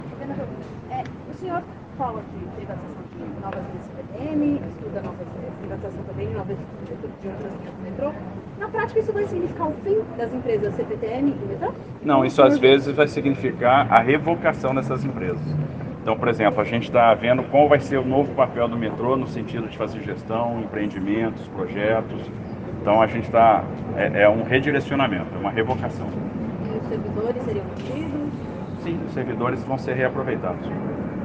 Em entrega do primeiro trem zero quilômetro da ViaMobilidade para as linhas 8-Diamante e 9-Esmeralda, nesta quinta-feira, 08 de junho de 2023, Tarcísio foi questionado se as concessões previstas na área de trilhos vão significar o fim do Metrô e da CPTM (Companhia Paulista de Trens Metropolitanos).